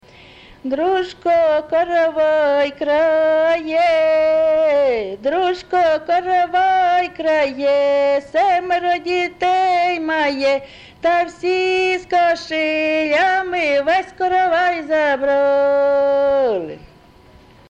ЖанрВесільні
Місце записус. Яблунівка, Костянтинівський (Краматорський) район, Донецька обл., Україна, Слобожанщина